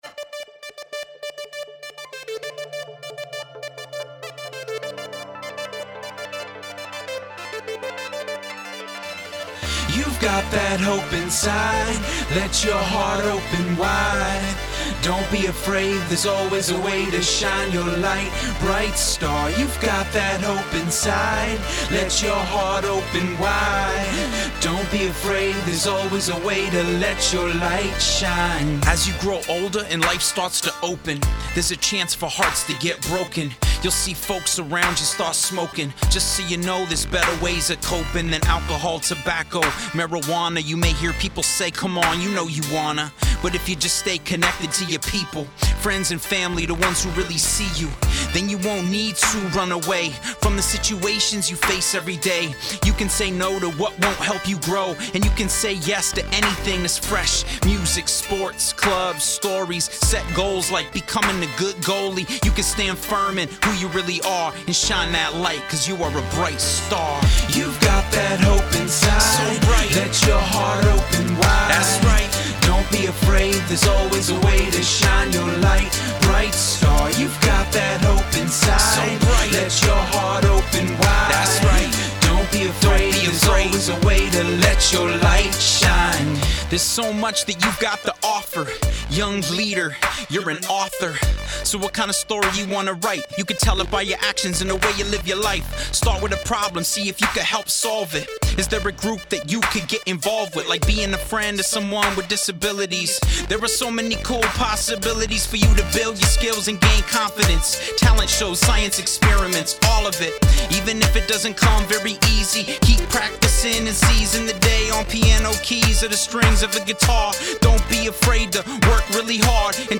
fun, high energy song